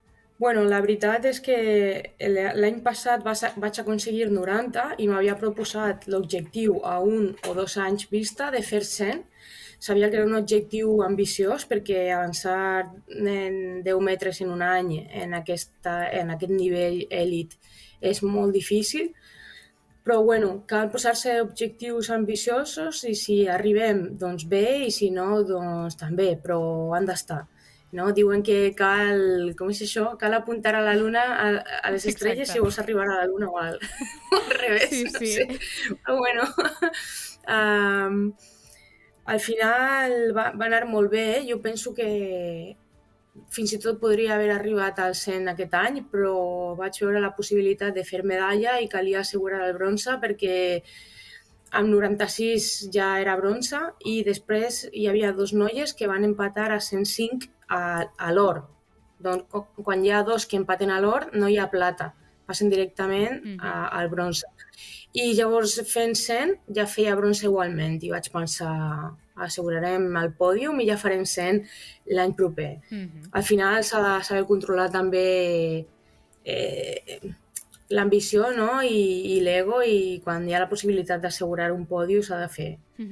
En una entrevista concedida al programa El Supermatí